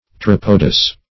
Pteropodous \Pte*rop"o*dous\, a.
pteropodous.mp3